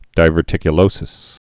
(divûr-tikyə-lōsis)